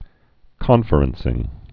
(kŏnfər-ən-sĭng, -frən-sĭng)